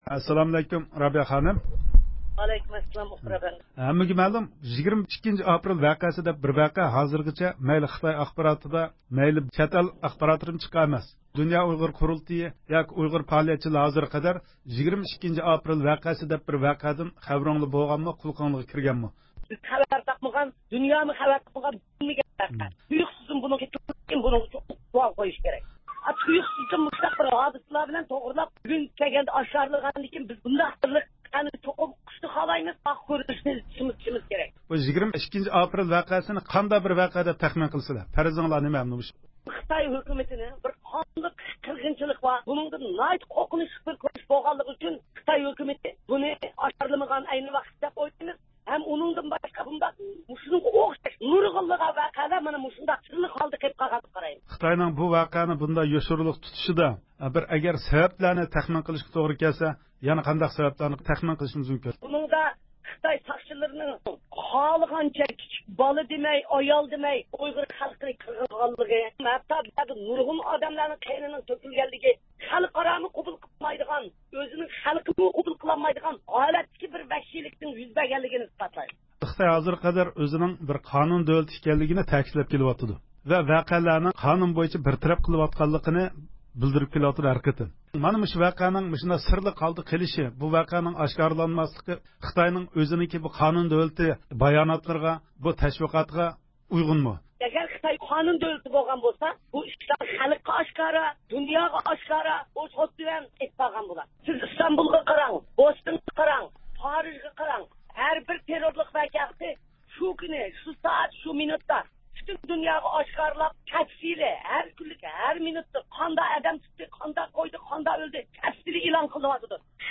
بىز ۋەقە ھەققىدىكى پەرەز ۋە چاقىرىقلىرىنى بىلىش ئۈچۈن رابىيە خانىم بىلەن سۆھبەت ئېلىپ باردۇق.